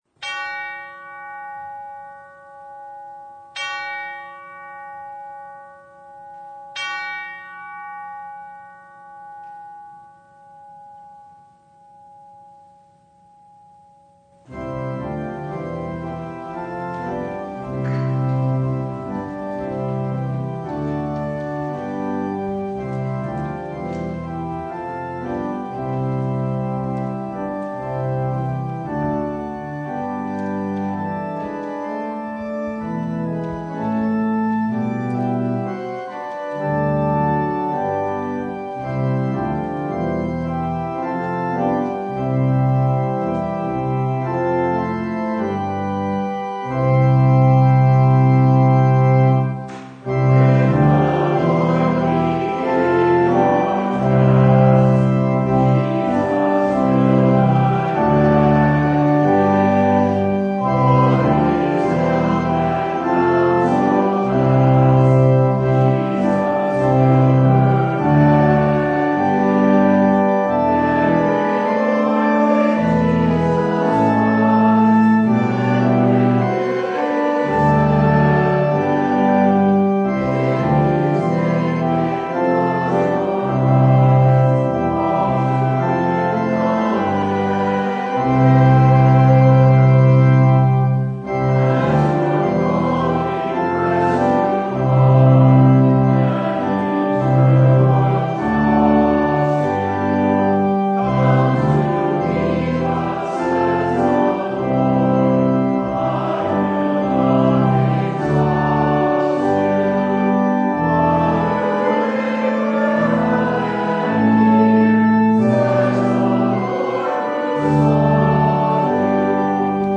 Matthew 20:1–16 Service Type: Sunday It’s more than wages in the kingdom of heaven.
Download Files Notes Bulletin Topics: Full Service « Who’s Counting?